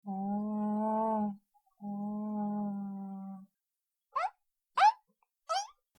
Typische Wandlinggeräusche: Lautäußerung des Wohlbehagens und warnendes Quieken